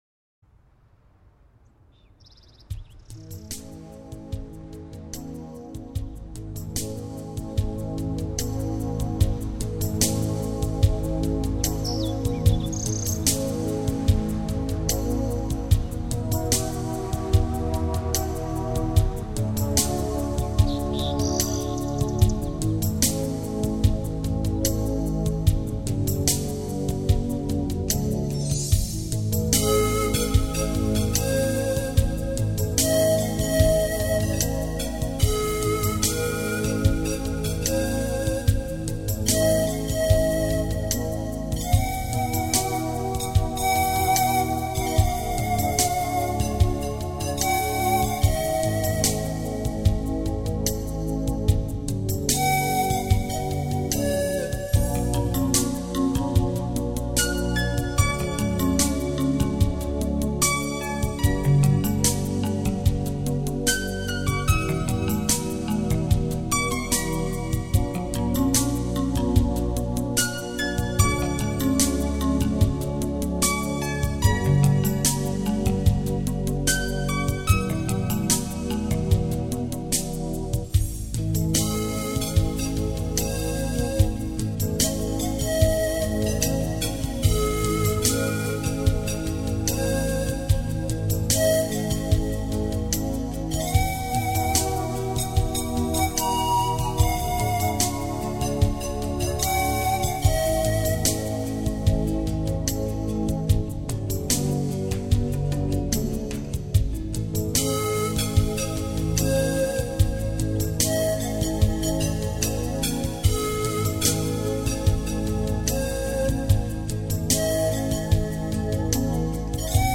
全碟大量的使用了直笛和排萧的吹奏，键盘及敲击乐器等传统南美乐器，加上隐隐的和声，揉出一派纯真的南美风格。